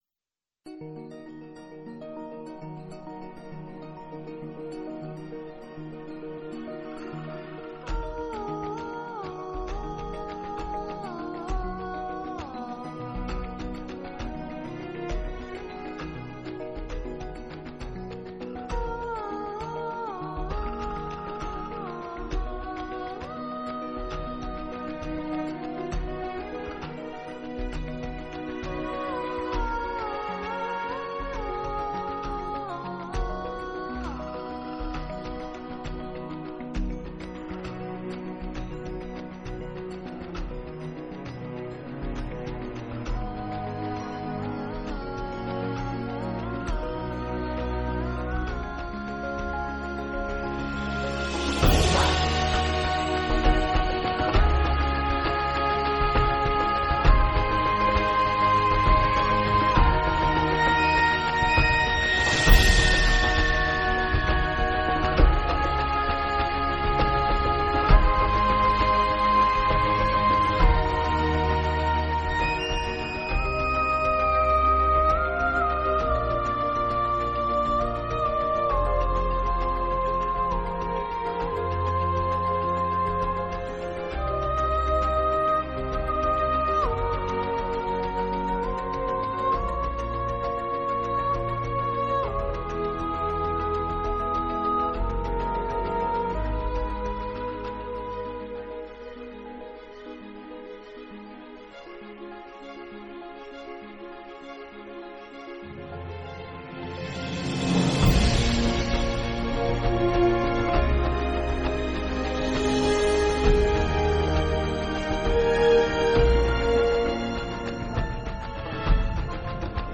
Soundtrack, Orchestral